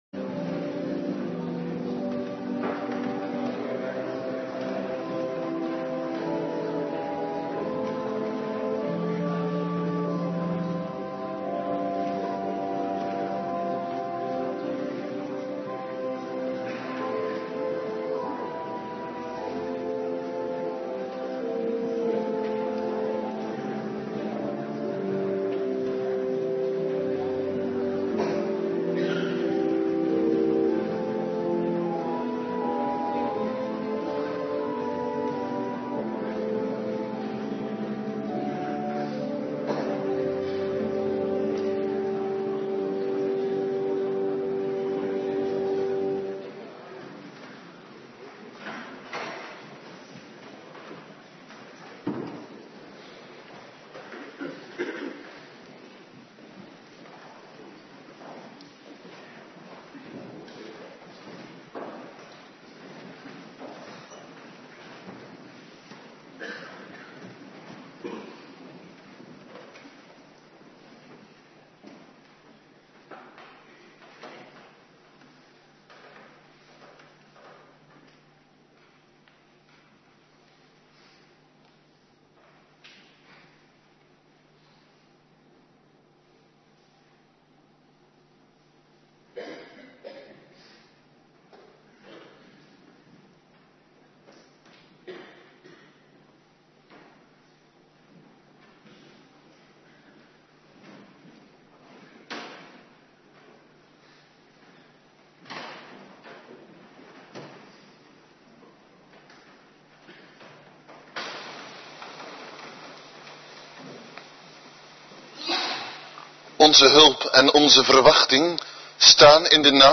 Avonddienst
Locatie: Hervormde Gemeente Waarder